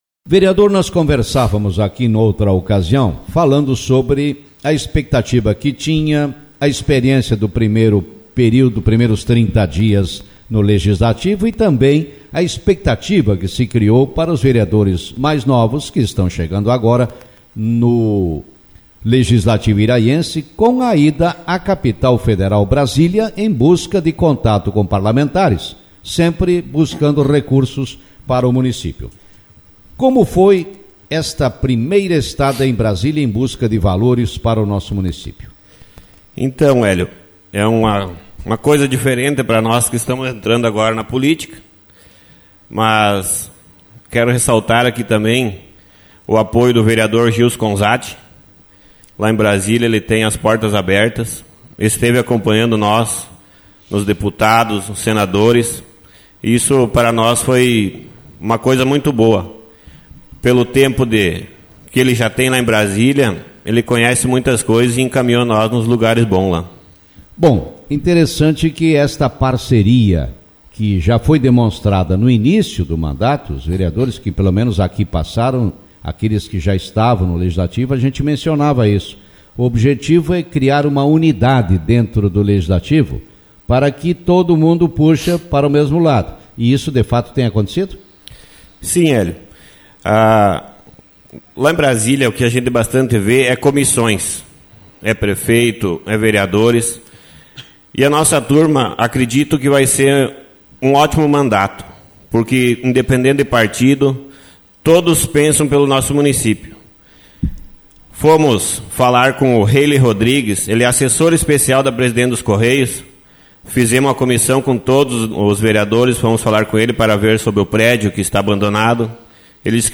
Vereador Miguel Ângelo Strozak, MDB, avalia viagem a Brasília Autor: Rádio Marabá 06/03/2025 Manchete Na manhã desta quinta-feira, o vereador iraiense MIGUEL ÂNGELO STROZAK, MDB, concedeu entrevista e falou sobre sua estada na Capital Federal, Brasília, quando na ocasião em companhia dos demais vereadores de Iraí, visitou vários gabinetes de parlamentares, tanto na Câmara dos Deputados como também no Senado da República.